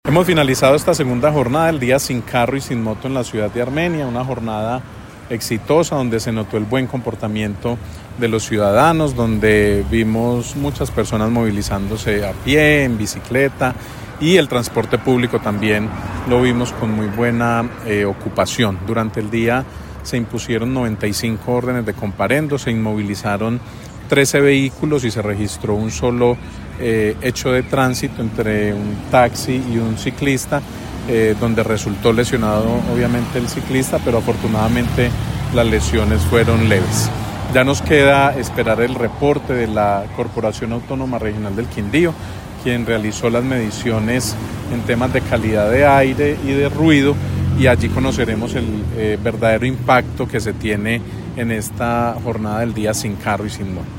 Secretario de Tránsito de Armenia